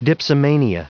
Prononciation du mot dipsomania en anglais (fichier audio)
Prononciation du mot : dipsomania